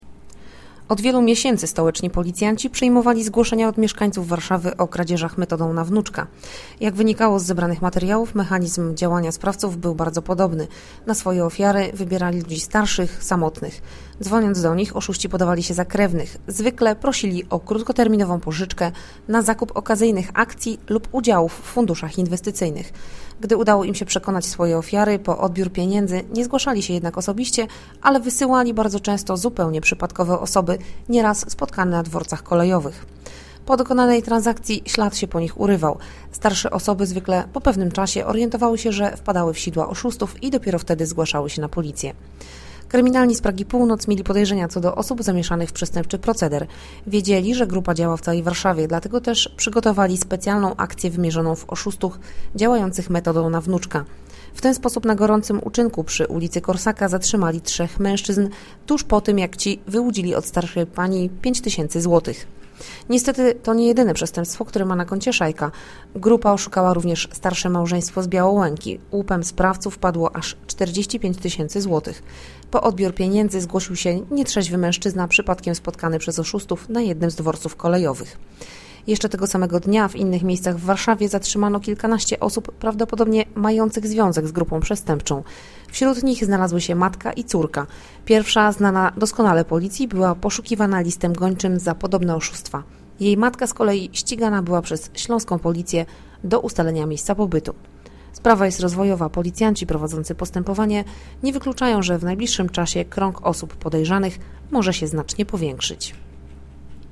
Nagranie audio Mówi